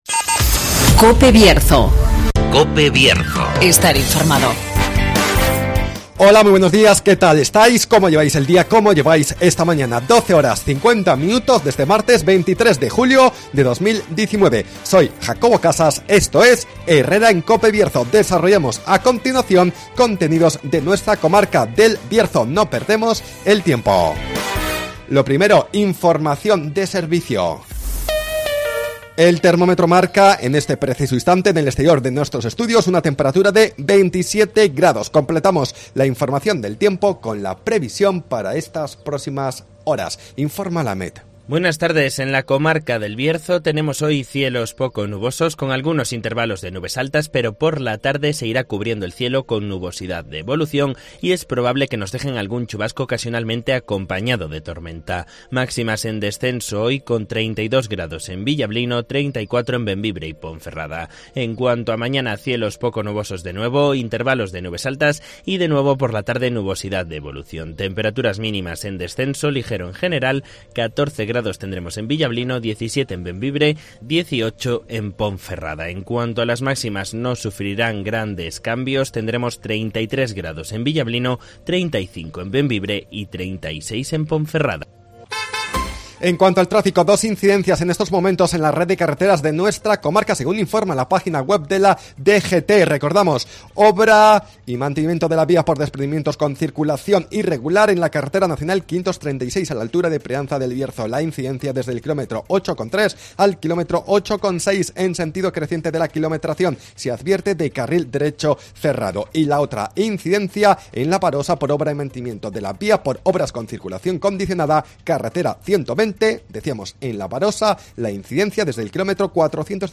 AUDIO: Repasamos la actualidad y realidad del Bierzo. Espacio comarcal de actualidad, entrevistas y entretenimiento.